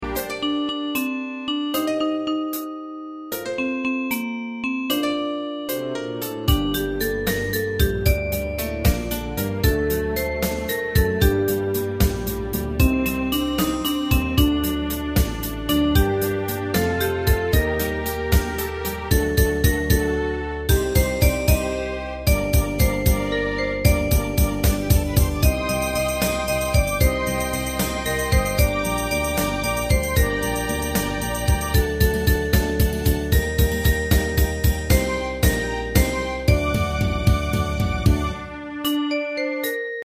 大正琴の「楽譜、練習用の音」データのセットをダウンロードで『すぐに』お届け！
Unison musical score and practice for data.